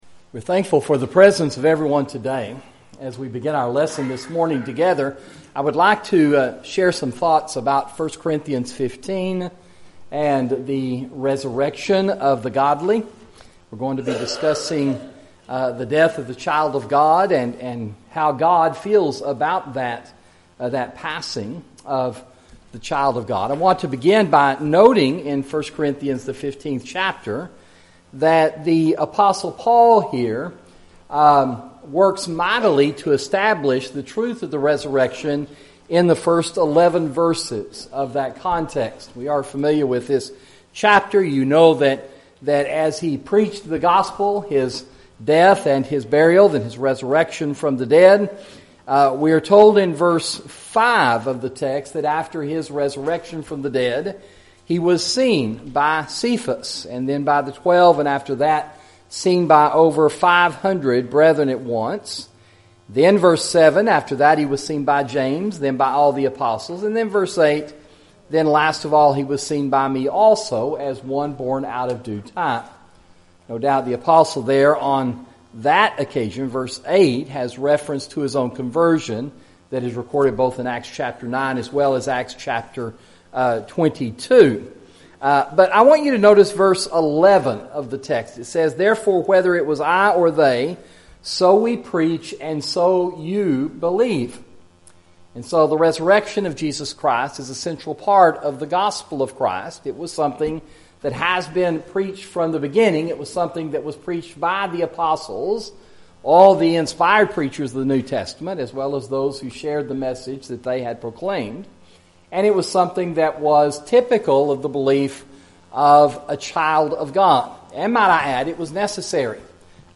Sermon: The Death of a Christian – Sound Teaching